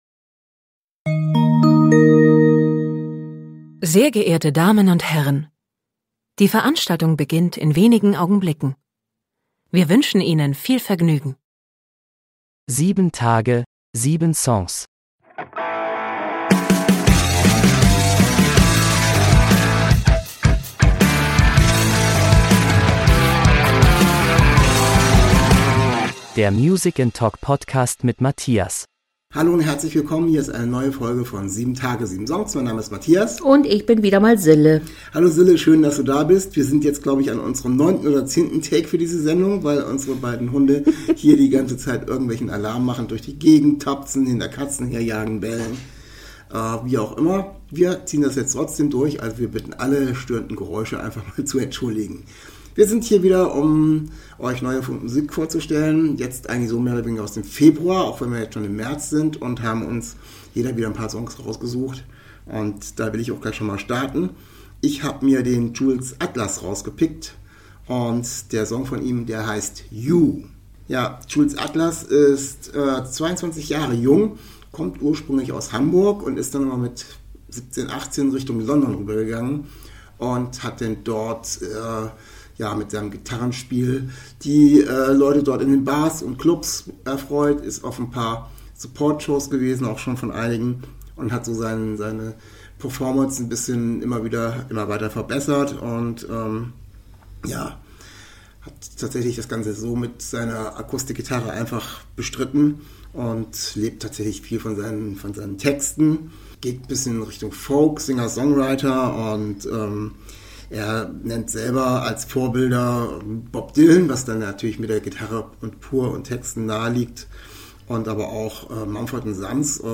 Von Folk bis Punk, von Streichern, über Trompeten bis hin zum Walzer gibt es eine sehr Interessante Musikmischung.